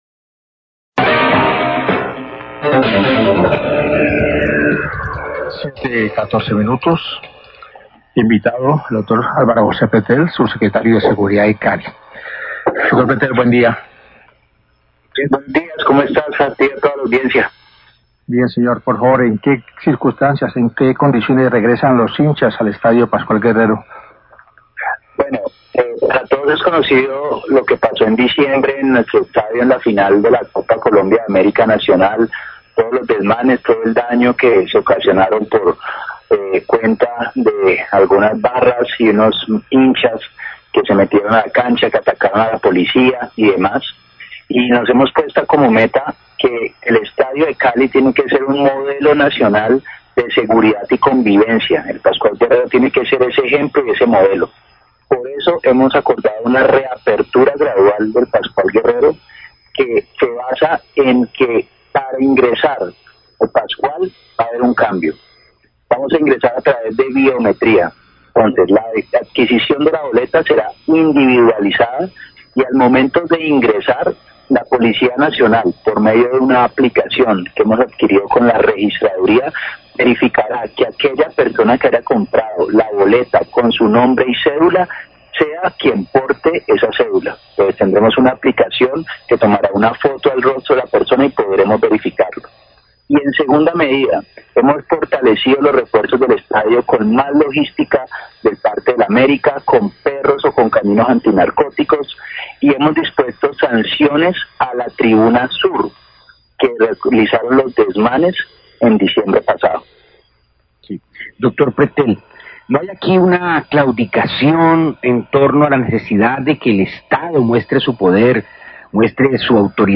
Subsecretario de Seguridad sobre apertura de estadio Pascual Guerrero, Noticiero relámpago, 714am
Subsecretario de Seguridad, Alvaro Pretel, habló acerca de las medidas de seguridad para la reapertura del estadio Pascual Guerrero para partido del América de Cli y las condiciones que deberán cumplir los hinchas que deseen ingresar.